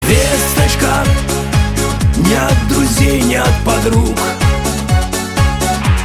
• Качество: 320, Stereo
русский шансон